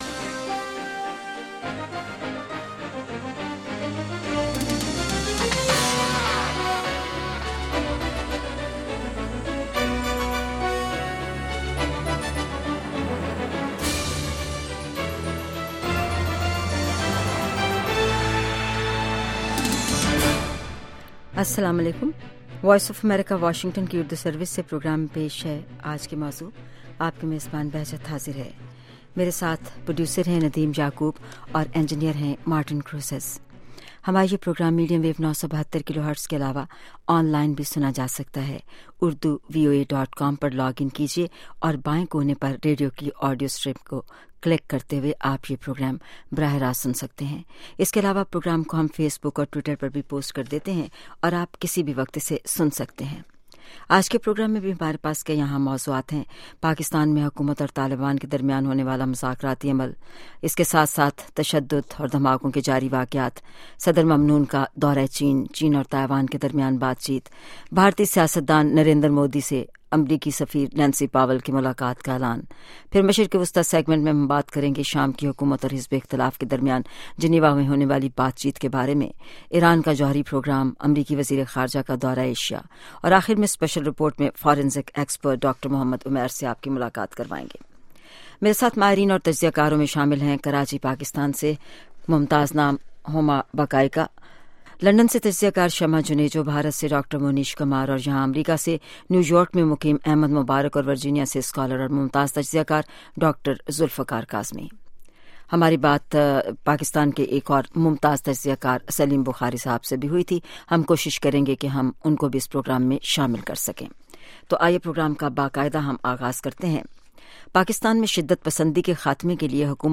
Sound Bites
Special Report